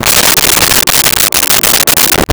Glass Slide Only 01
Glass Slide Only 01.wav